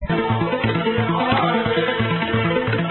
3 campionamenti di canti di nativi americani